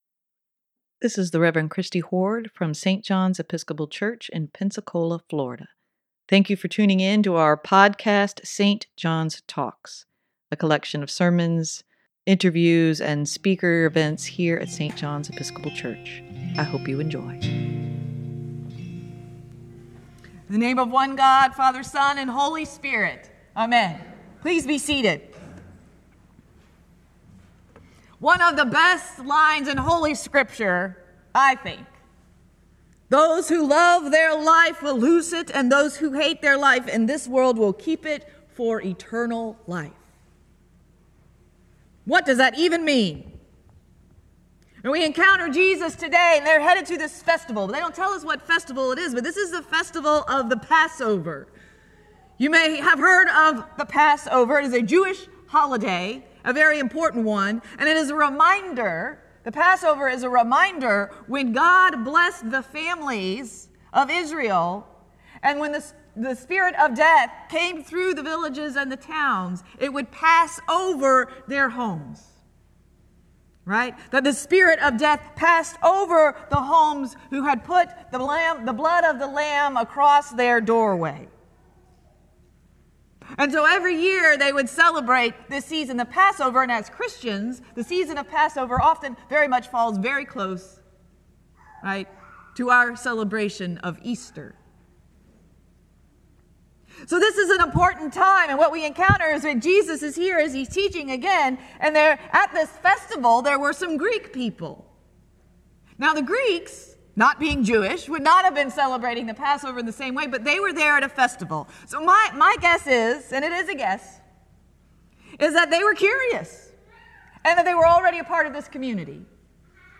Sermon for March 17, 2024: Submitting to Jesus - St. John's Episcopal Church